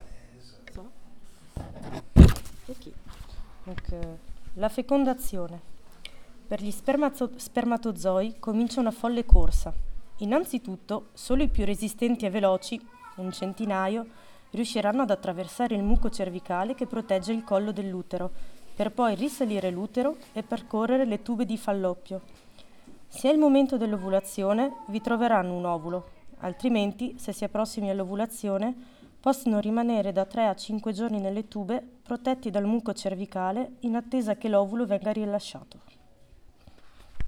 Cet espace invitait également à la flânerie, de nombreuses personnes se sont installées pour découvrir les albums et certaines nous ont fait le cadeau d’une lecture dans différentes langues.